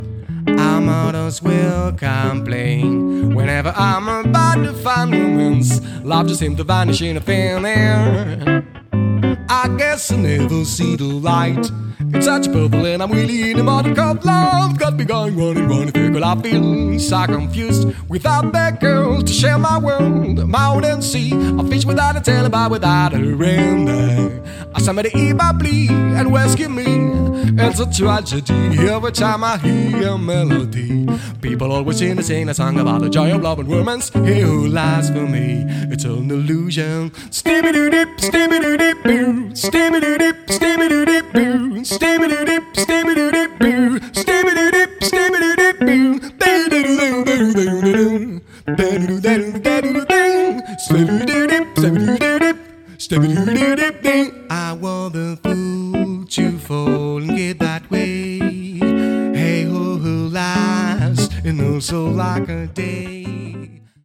en concert
guitare
saxophone ténor
chant